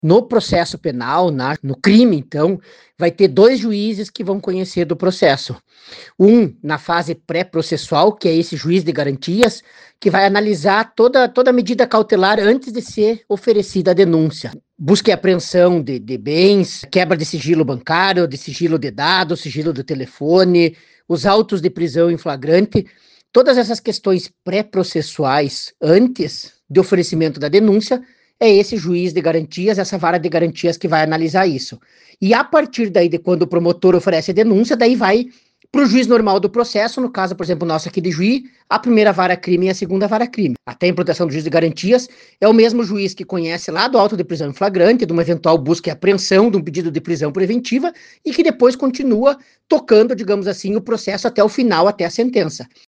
O Juiz da Primeira Vara Criminal do Fórum de Ijuí, Eduardo Gioveli, explica que o Juiz de Garantias fica responsável por toda fase pré-processual, por exemplo, oferecer denúncia contra réus.